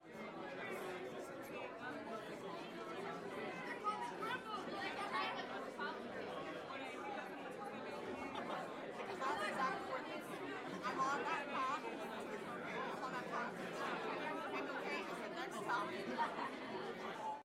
Звуки ночного клуба
Шум разговора толпы в ночном клубе без музыки